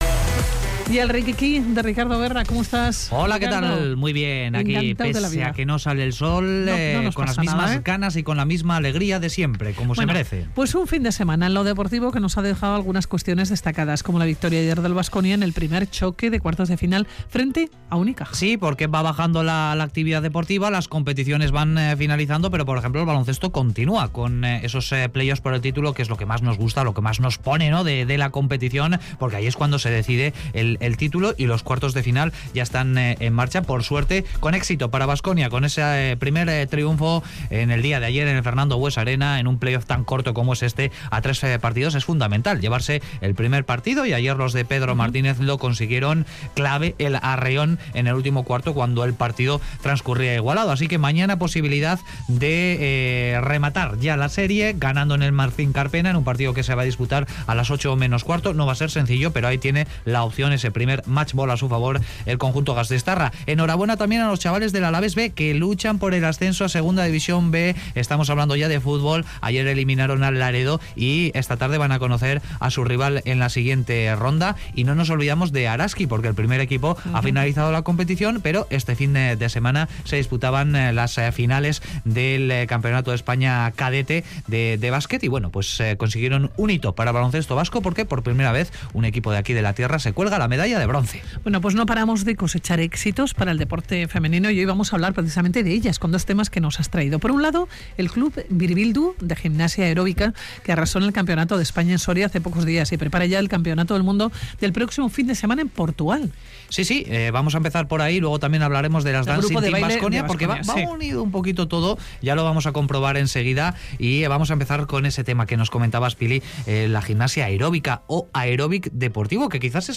Audio: Entrevista con el cub Biribildu de Vitoria y con el Dancing Team Baskonia